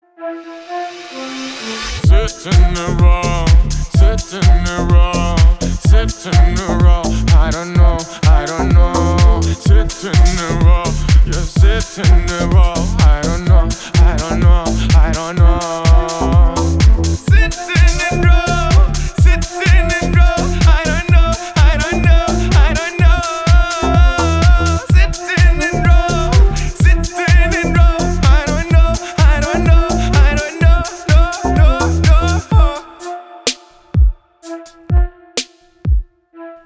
• Качество: 320, Stereo
ритмичные
мужской вокал
Хип-хоп
качает